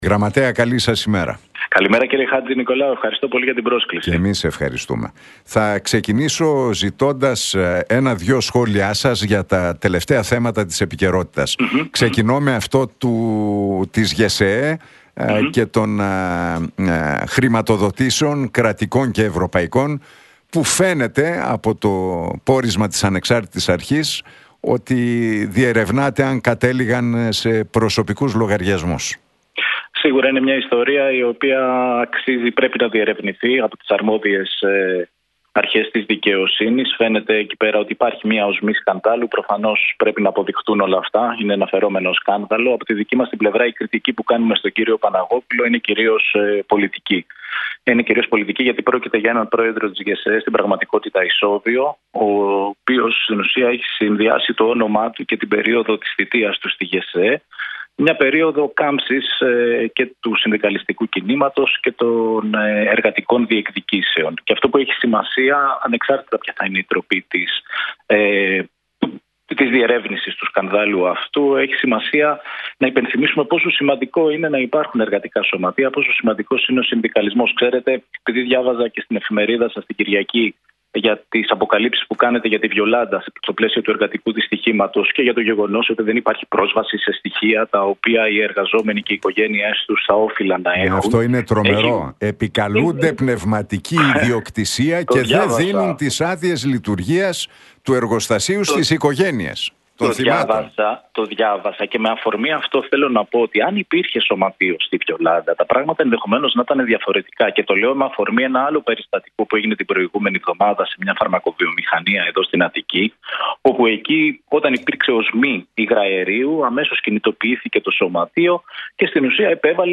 Για την υπόθεση Παναγόπουλου, τη Συνταγματική Αναθεώρηση, την τραγωδία στη Χίο και τις ελληνοτουρκικές σχέσεις μίλησε ο Γραμματέας της Νέας Αριστεράς, Γαβριήλ Σακελλαρίδης στον Realfm 97,8 και την εκπομπή του Νίκου Χατζηνικολάου.